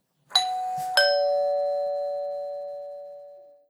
door-bell.wav